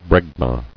[breg·ma]